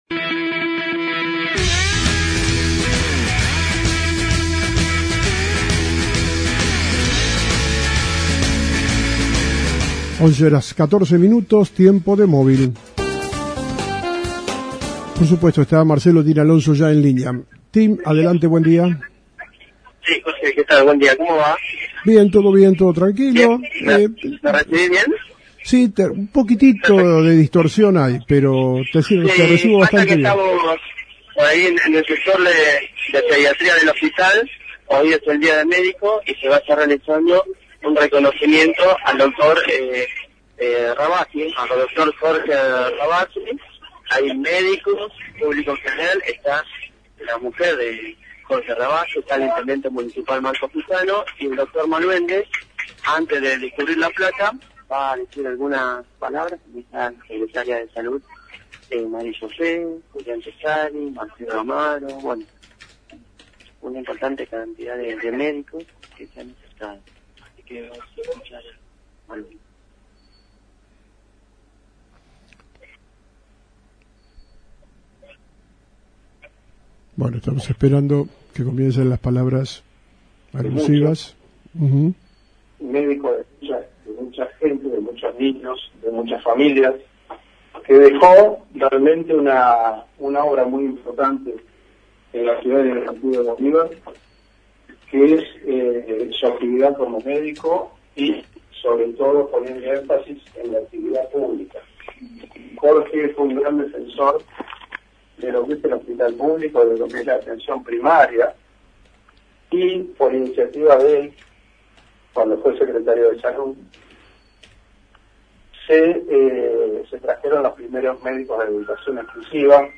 En el Día del Médico se Realizó un Reconocimiento
Palabras Alusivas